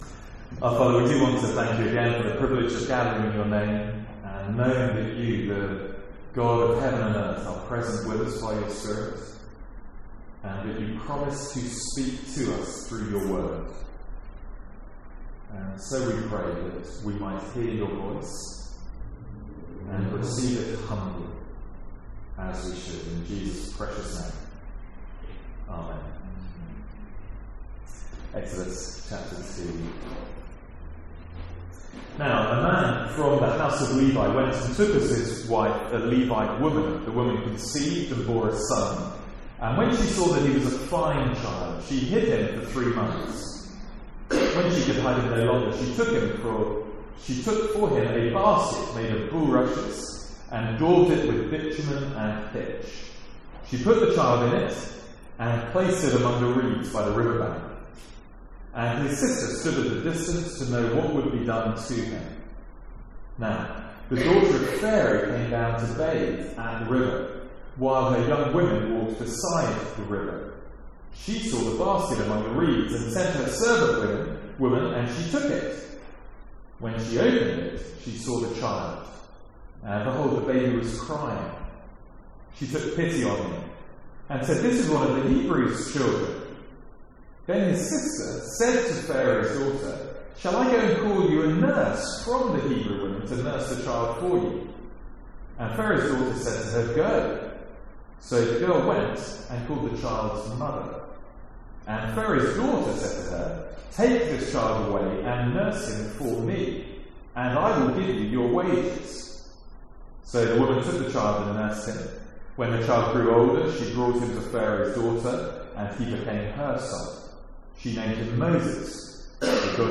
Sermons | St Andrews Free Church
(N.B. Poor audio quality due to a technical error with the recording).